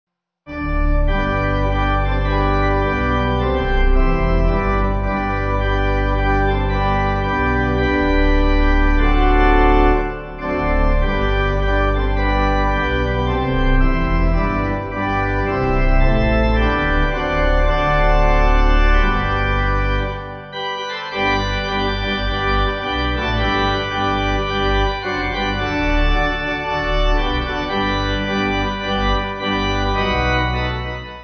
(CM)   3/G